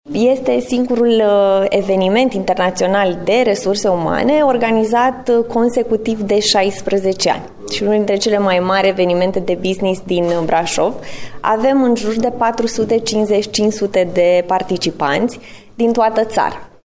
Managerul de selecţie, training şi dezvoltare al unei firme de resurse umane